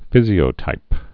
(fĭzē-ō-tīp)